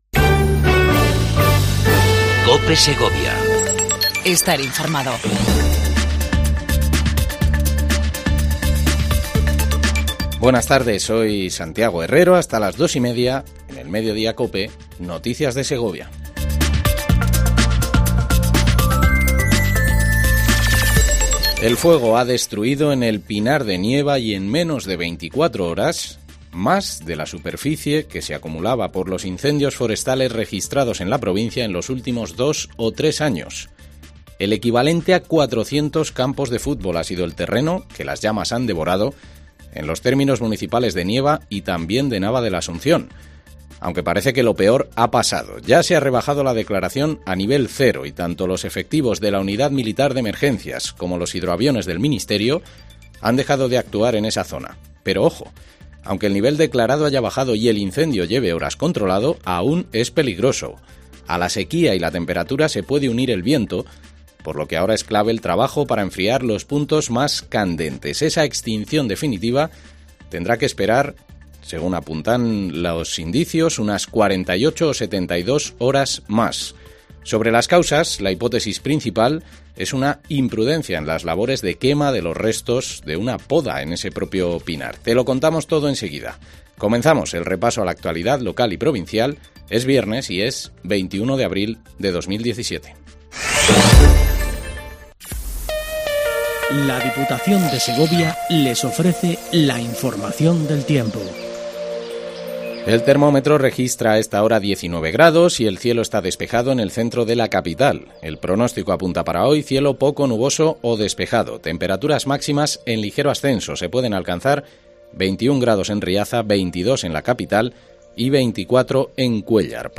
INFORMATIVO MEDIODIA CIPE EN SEGOVIA 21 04 17